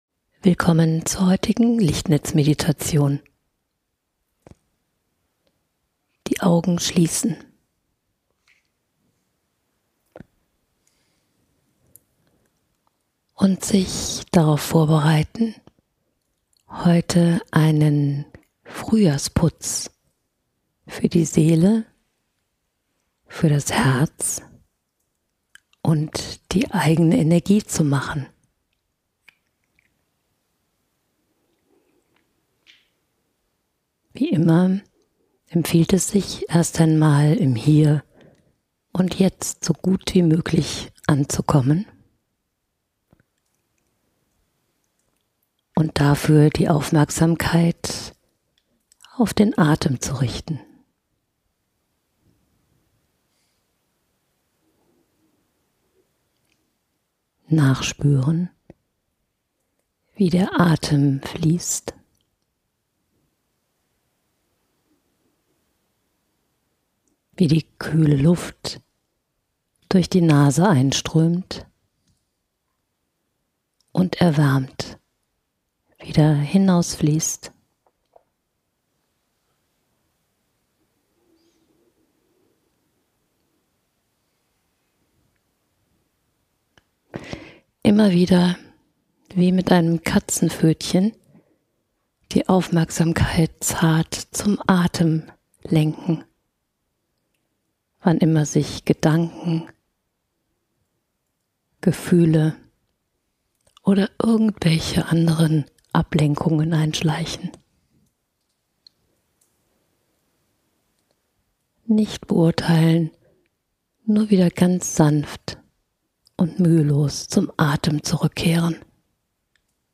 Beschreibung vor 1 Jahr Diese Meditation ist der leichteste, entspannendste und regenerierenste Frühjahrsputz, den du dir vorstellen kannst. Mit sanften Atemzügen, aber auch mit sanften Besenstrichen eines „Lichtkammes“ wirst du auf zärtliche Weise alles los, was nicht mehr zu dir gehört, verbraucht ist oder dich unnötig belastet.